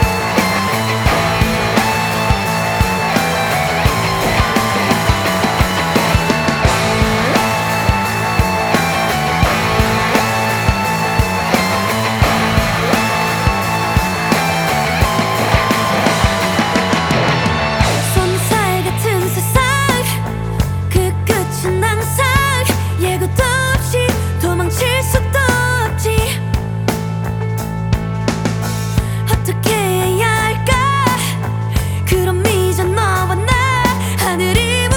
Жанр: Поп музыка / Рок
Rock, Pop, K-Pop